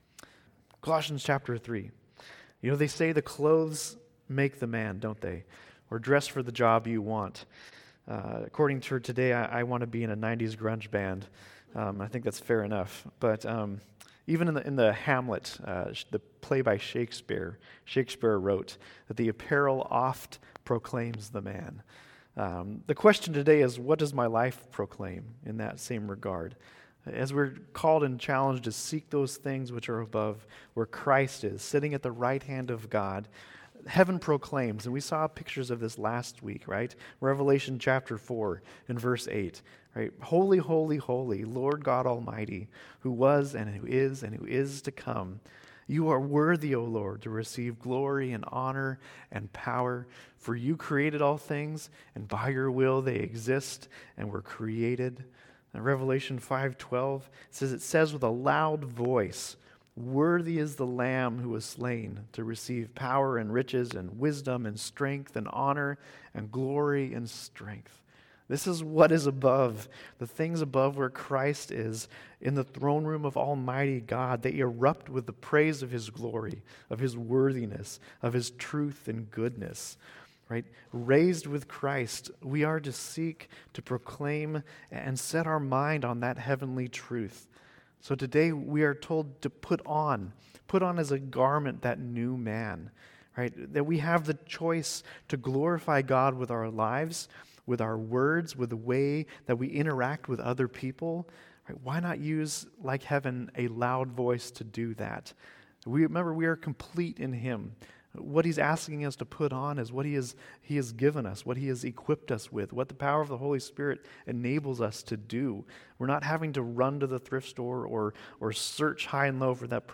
Sermons - Calvary Chapel Eureka